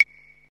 catch-banana.wav